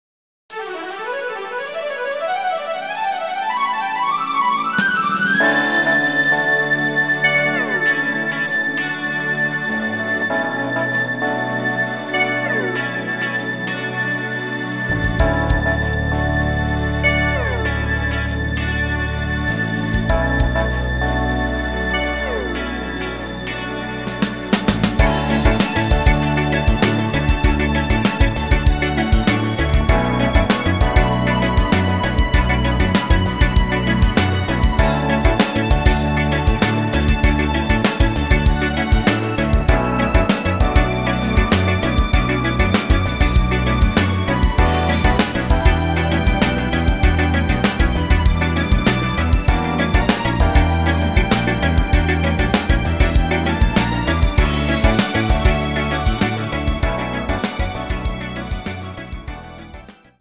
Rhythmus  Easy 16 Beat
Art  Instrumental Orchester, Neuerscheinungen